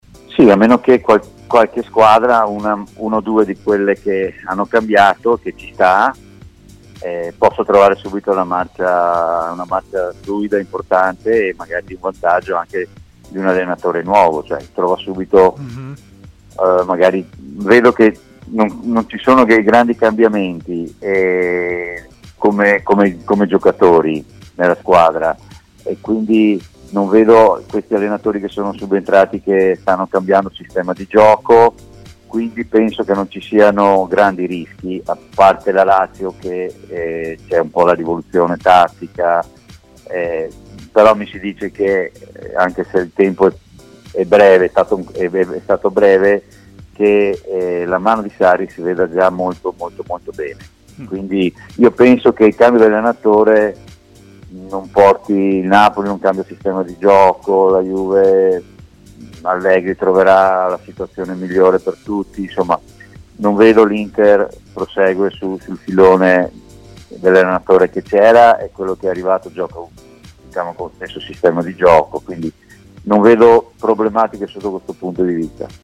Fonte: TMW Radio